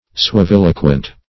Search Result for " suaviloquent" : The Collaborative International Dictionary of English v.0.48: Suaviloquent \Sua*vil"o*quent\, a. [L. suaviloquens; suavis sweet + loquens, p. pr. of loqui to speak.]
suaviloquent.mp3